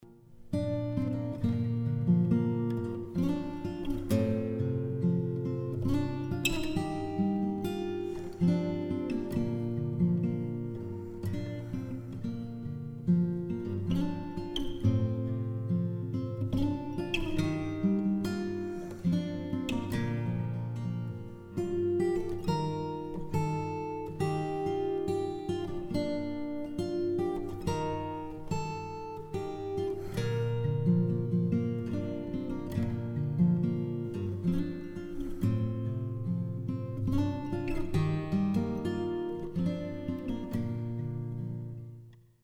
“Come Thou Fount” – Guitar
Guitar-Come-Thou-Fount.mp3